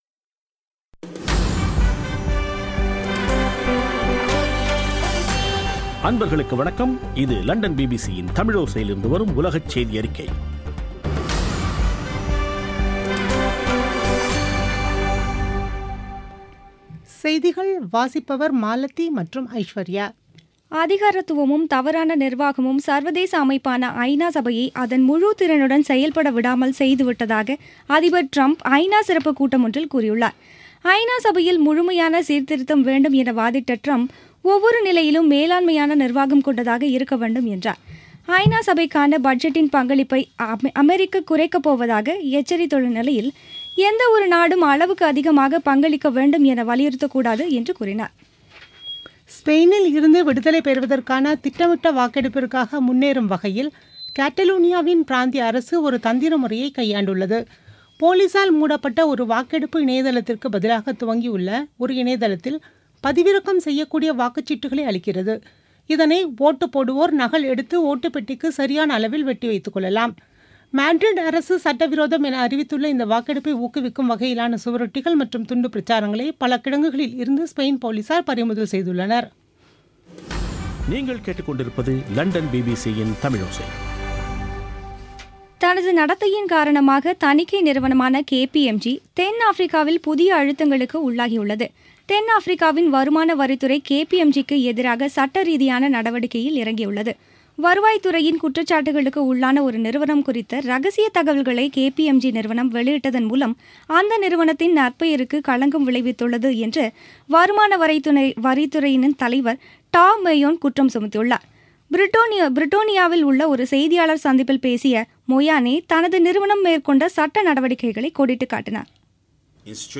பிபிசி தமிழோசை செய்தியறிக்கை (18/09/2017)